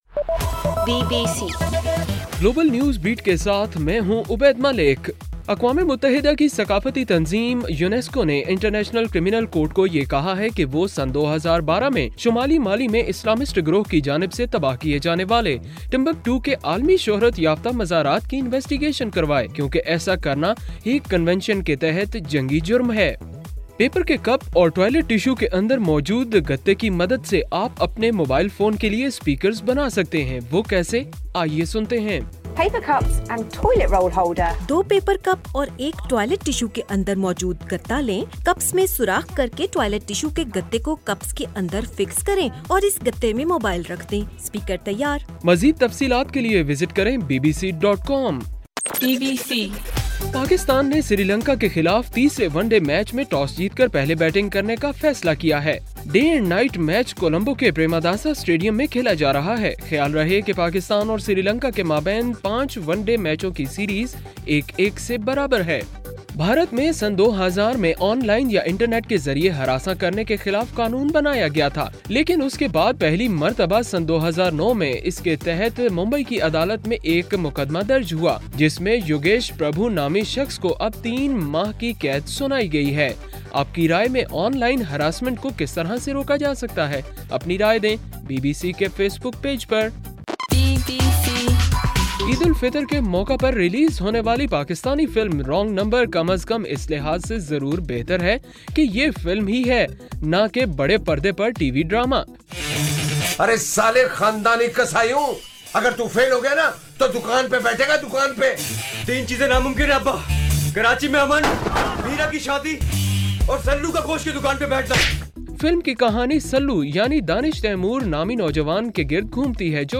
جولائی 19: رات 8 بجے کا گلوبل نیوز بیٹ بُلیٹن